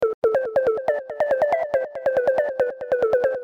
Sound10 - cинтез. мелодия